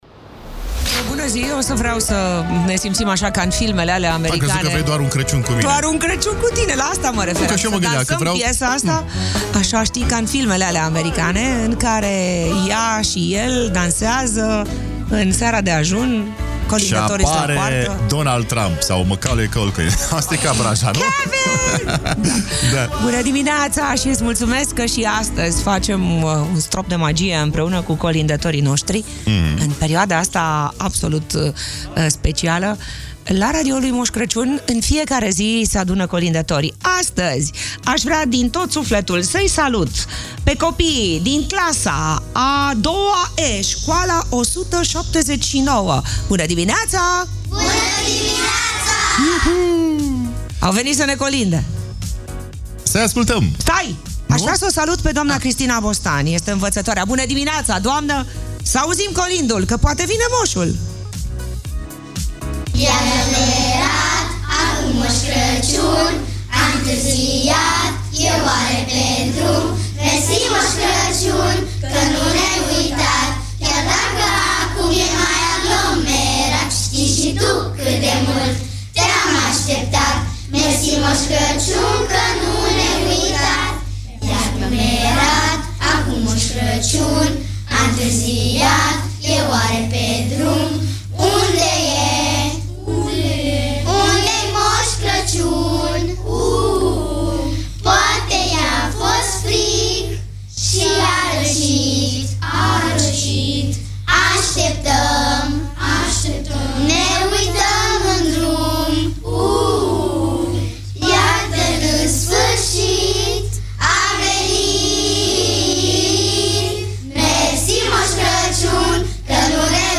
Magic Morning - 4 Decembrie - colindători, copiii din clasa a II-a E de la Școala Nr. 179